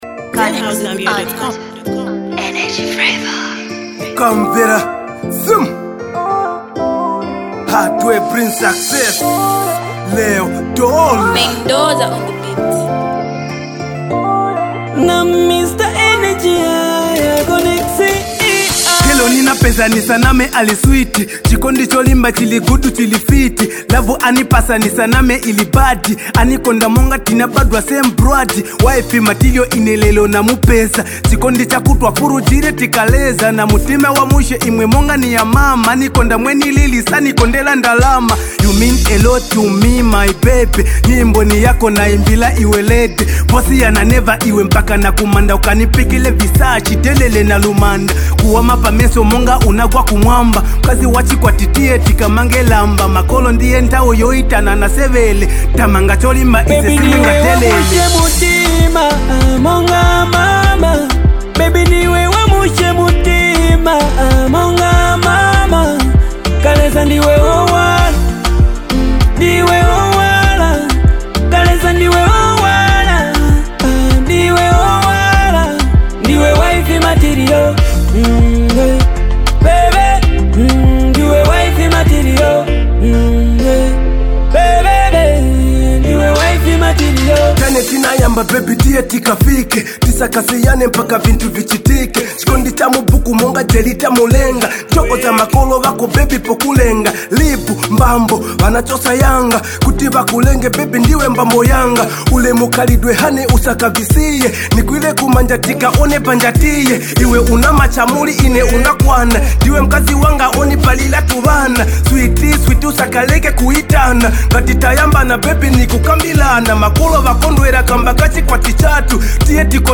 sweet love tune